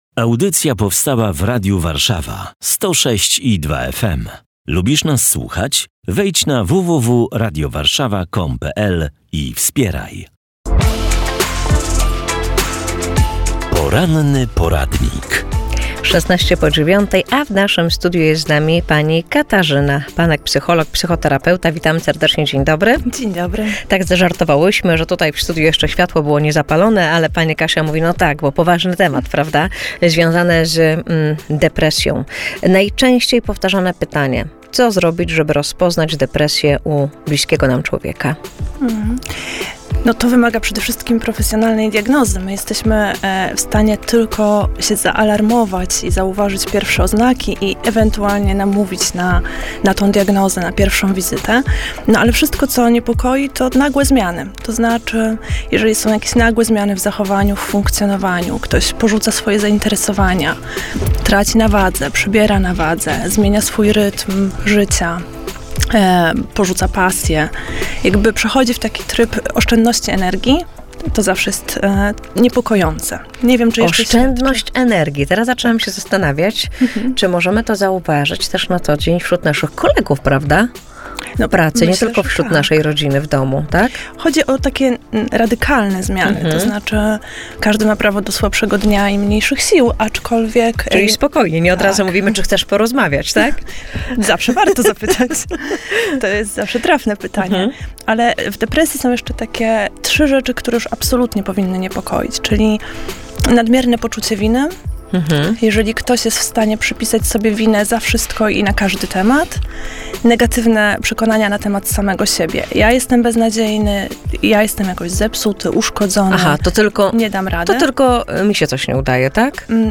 - Radio Warszawa 106,2 FM